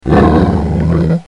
老虎 | 健康成长
tiger-sound.mp3